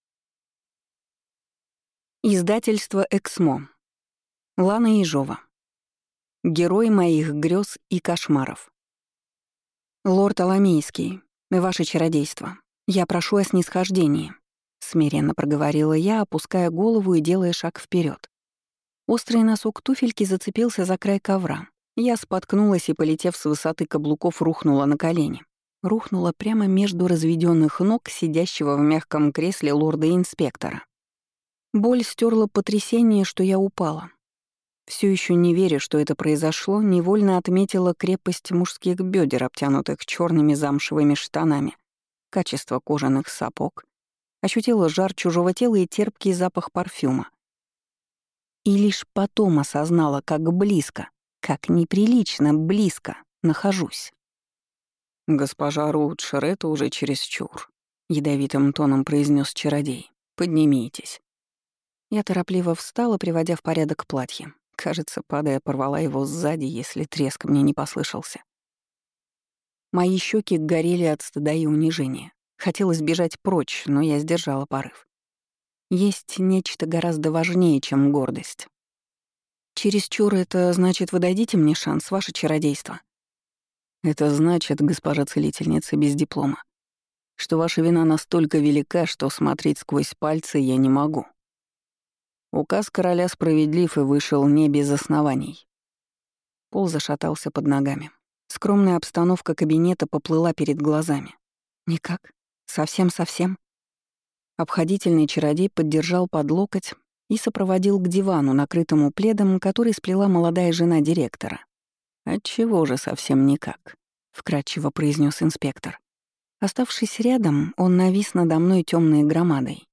Аудиокнига Герой моих грез и кошмаров | Библиотека аудиокниг